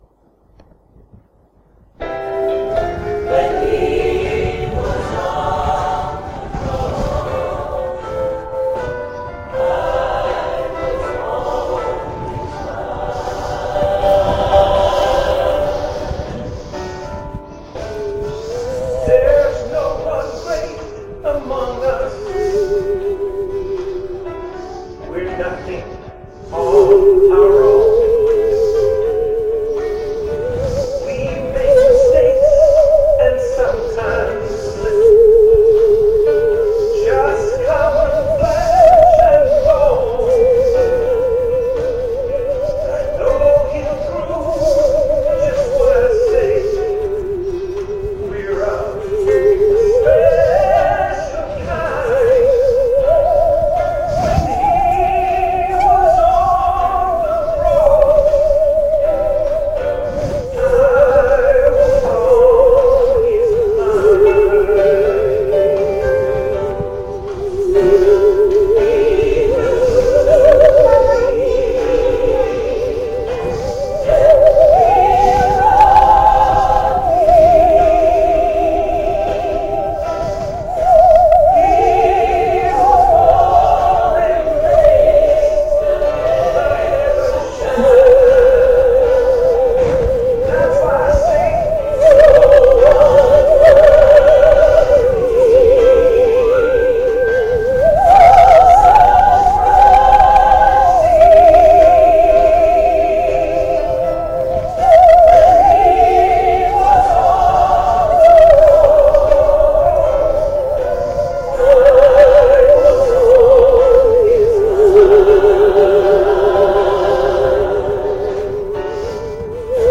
Special performances
Playing the Saw